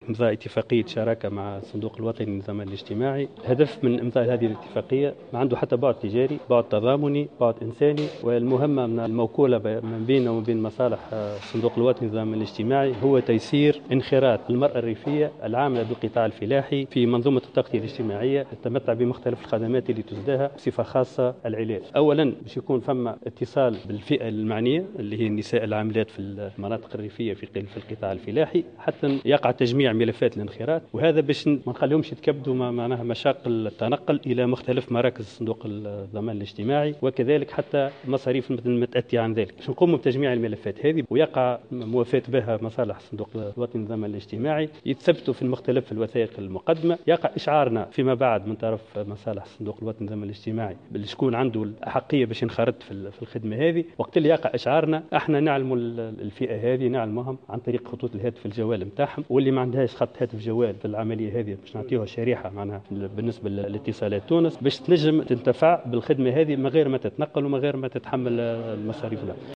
في تصريح لمراسلة الجوهرة اف ام